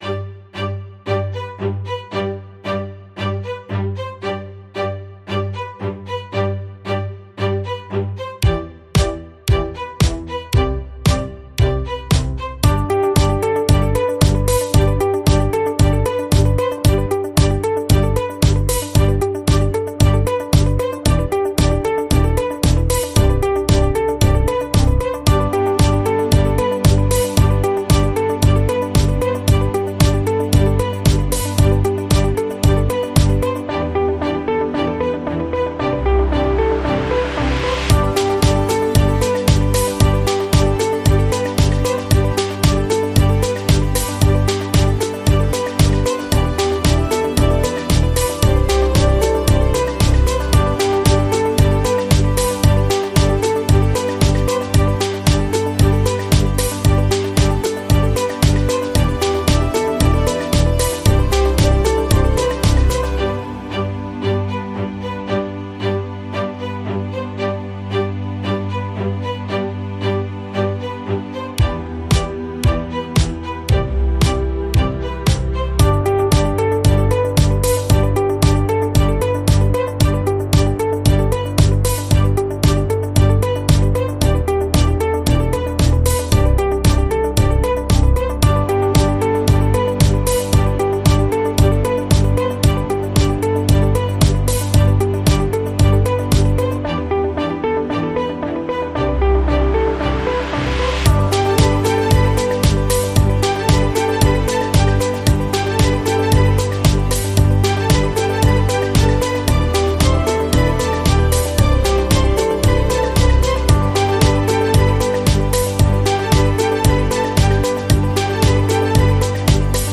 Pop track with strings